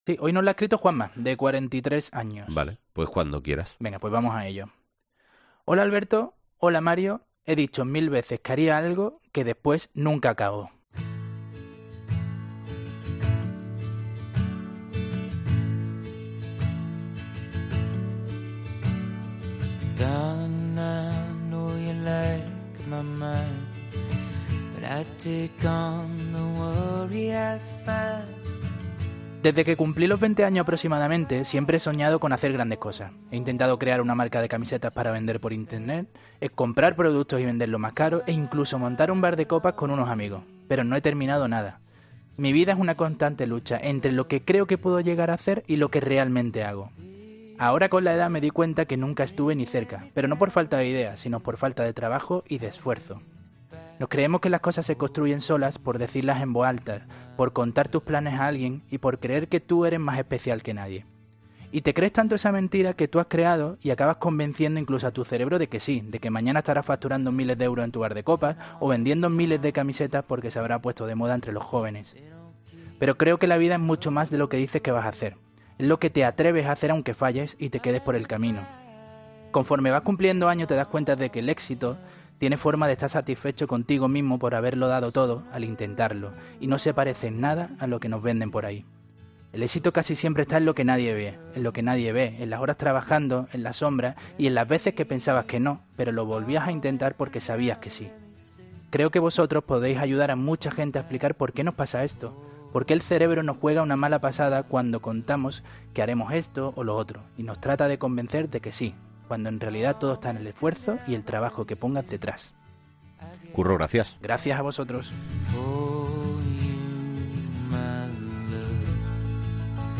lee la carta de un oyente que relata cómo la edad le ha enseñado que el verdadero triunfo no está en los grandes planes, sino en el esfuerzo silencioso